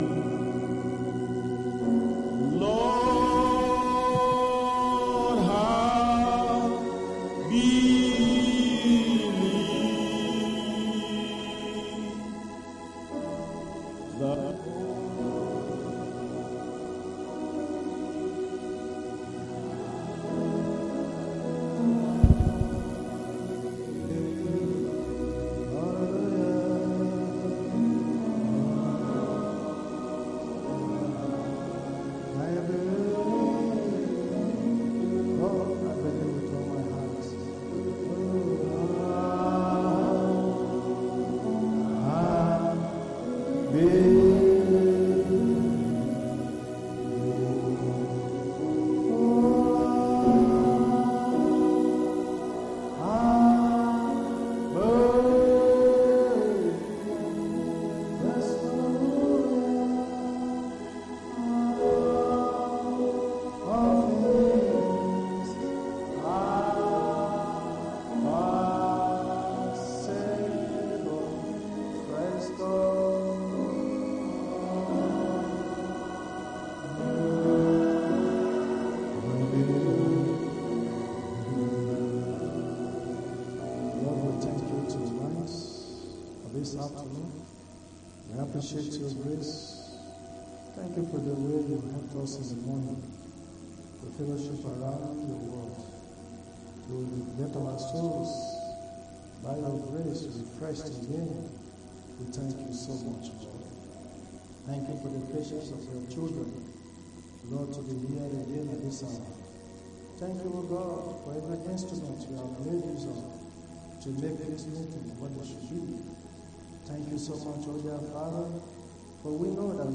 Sunday Afternoon Service